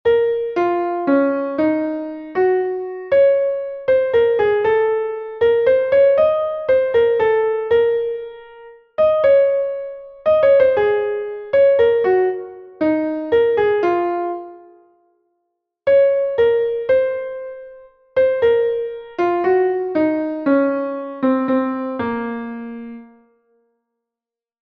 Entoación a capella
entonacioncapela10.4.mp3